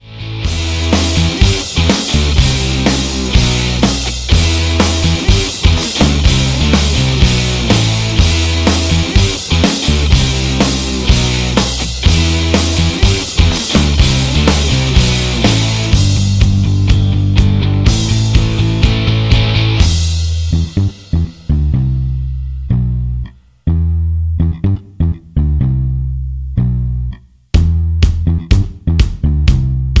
Backing track files: 2000s (3150)
Buy Without Backing Vocals
Buy With Lead vocal (to learn the song).